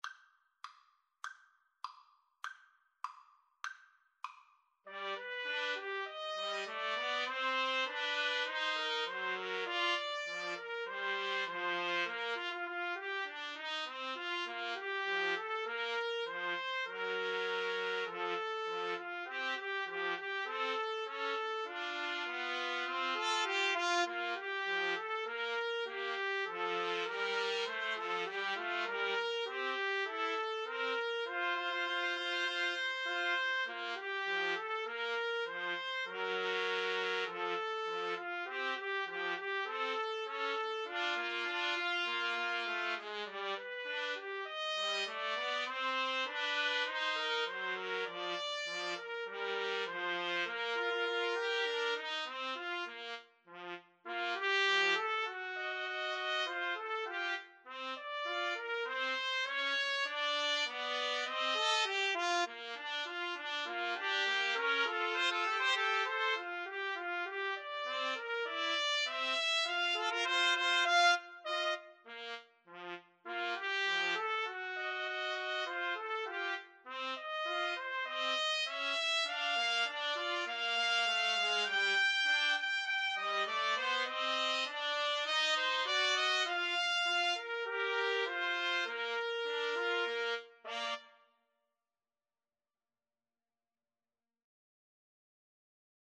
Trumpet 1Trumpet 2Trumpet 3
2/4 (View more 2/4 Music)
Tempo di Marcia
Pop (View more Pop Trumpet Trio Music)